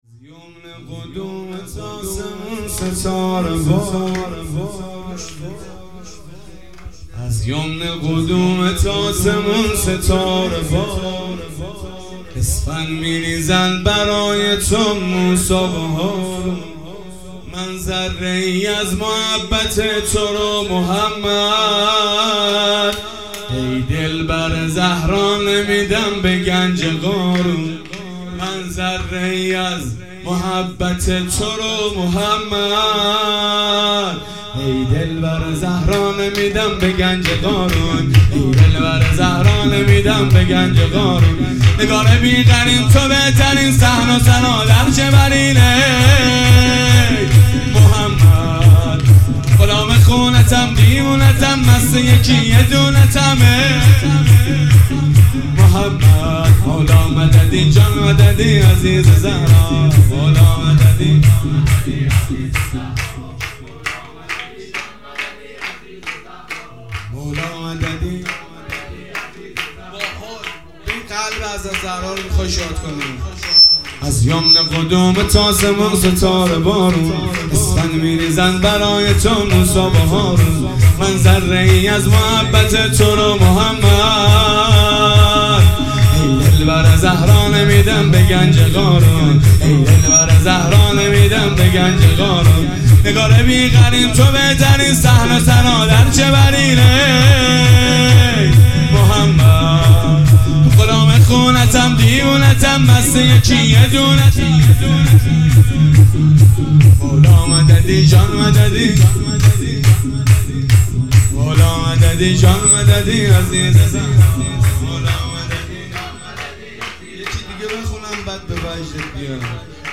جشن ولادت پیامبر(ص)۸-۸-۹۹